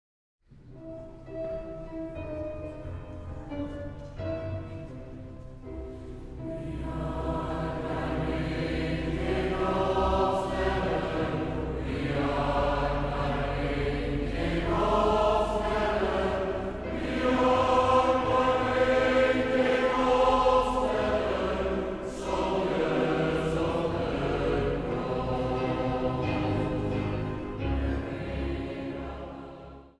Koor- en samenzang
Zang | Jongerenkoor
Zang | Mannenkoor